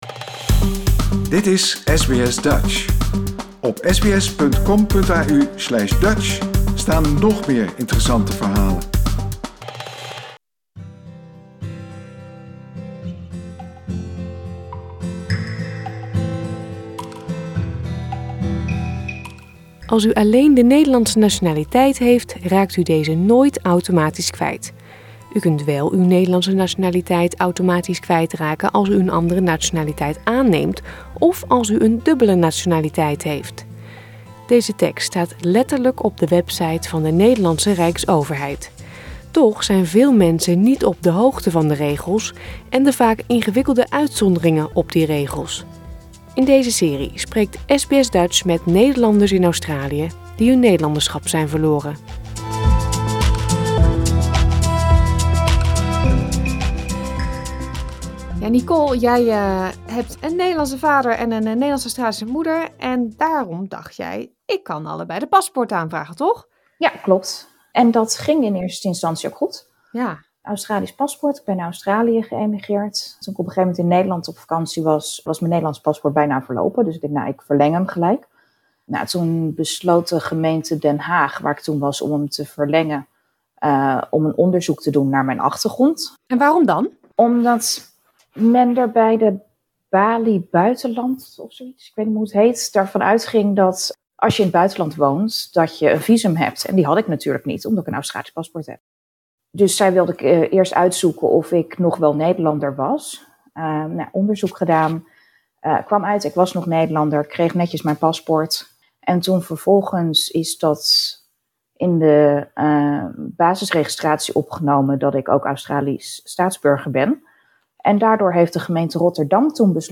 In deze serie spreekt SBS Dutch met Nederlanders in Australie die hun Nederlanderschap zijn verloren.